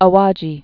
(ə-wäjē) or A·wa·ji·shi·ma (ə-wäjē-shēmə)